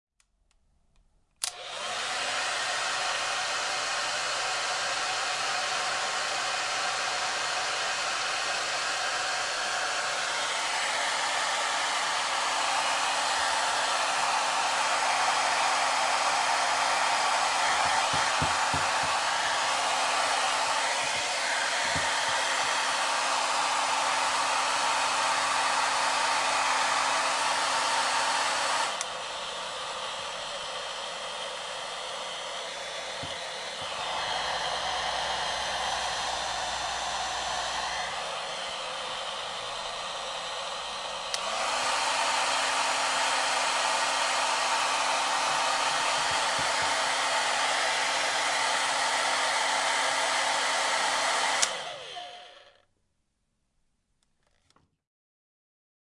吹风机 吹风机的声音效果 免费的高质量的声音效果
描述：吹风机吹风机音效免费高品质音效